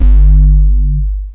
cx5 tuffast bas.wav